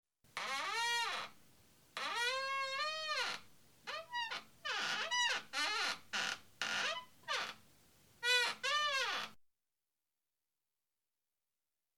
Tuerknarren.mp3